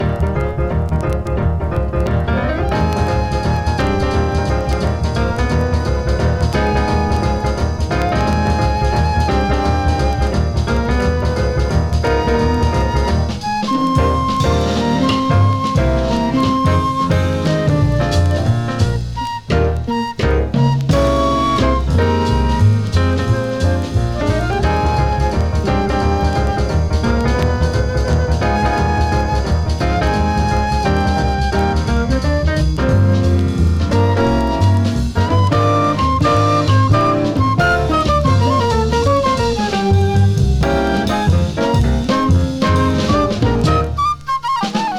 演奏者の持ち味がたっぷりで、軽やかでありながら濃密と感じるスウィングする良盤です。
Jazz, Swing, Cool Jazz　USA　12inchレコード　33rpm　Stereo